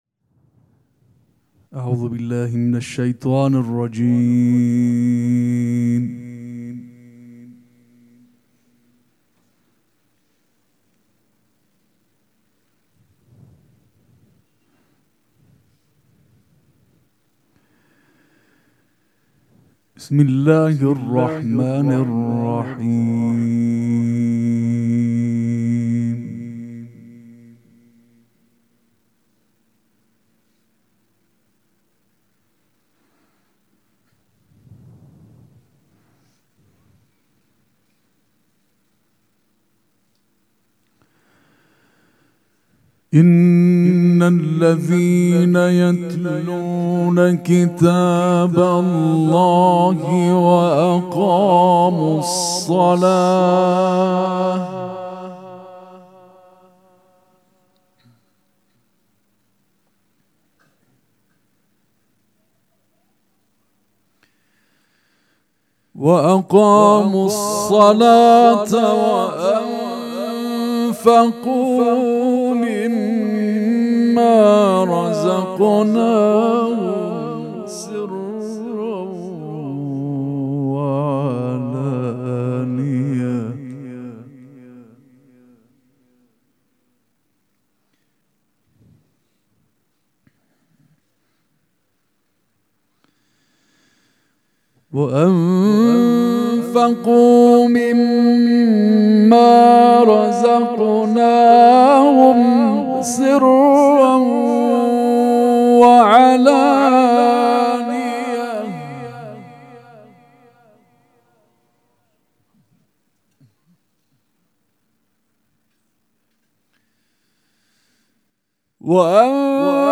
مراسم عزاداری شهادت امام صادق علیه‌السّلام
حسینیه ریحانه الحسین سلام الله علیها
قرائت قرآن